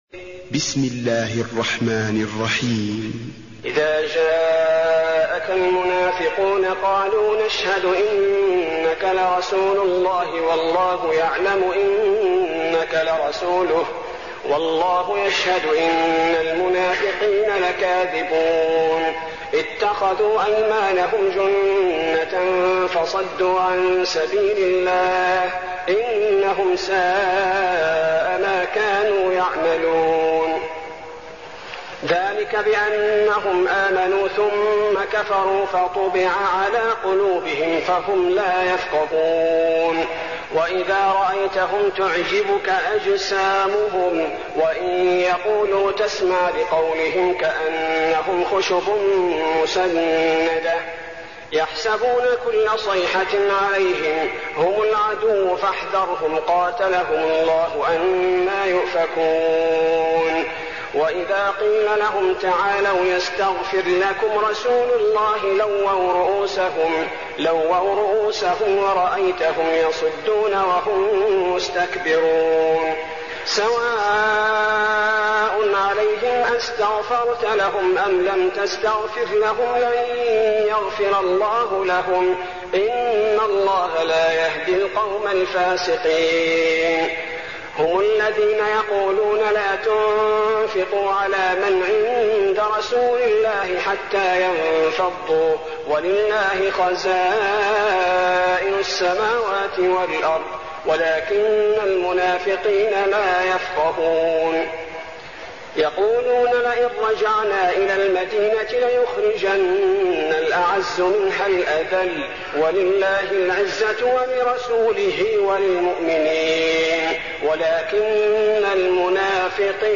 المكان: المسجد النبوي المنافقون